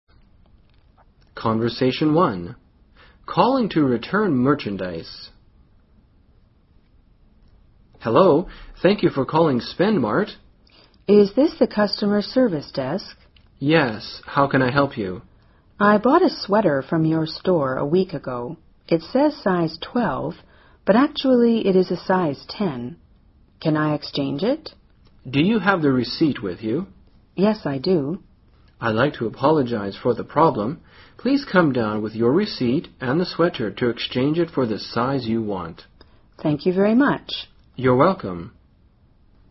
【对话1：打电话退货】